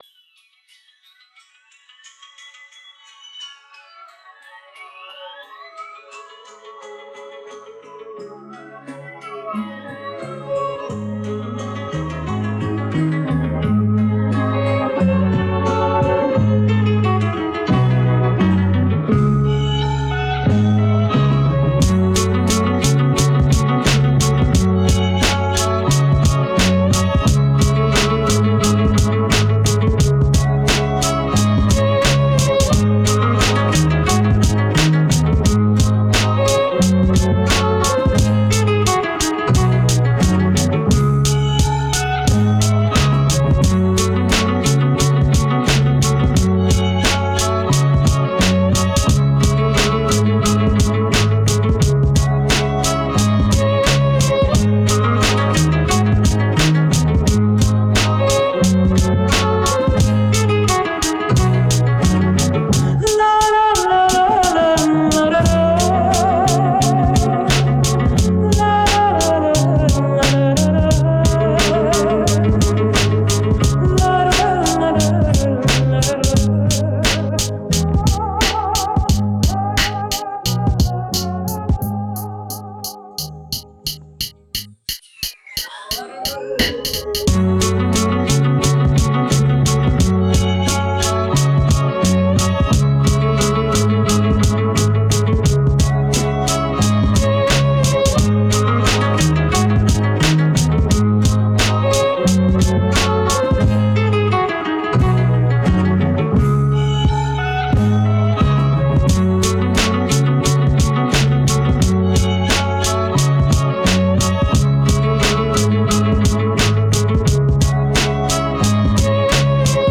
instrumental project